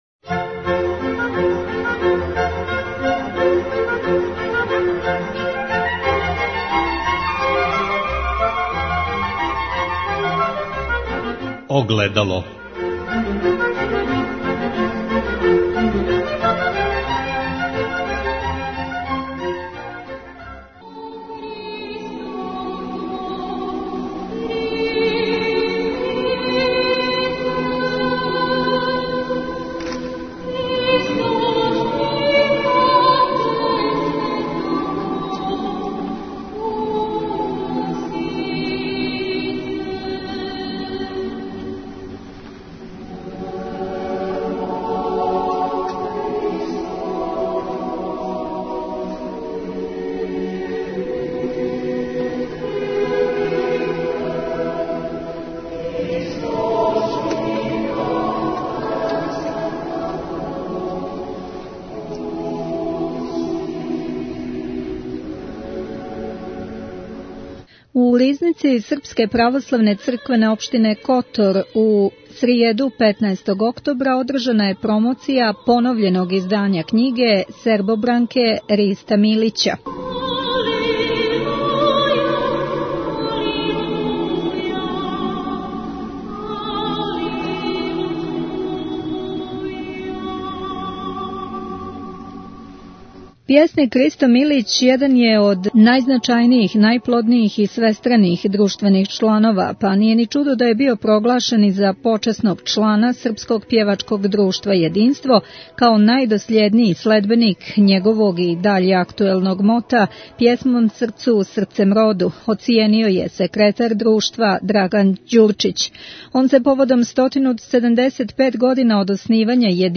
У Ризници СПЦО у Котору одржана је промоција књиге "Сербобранке"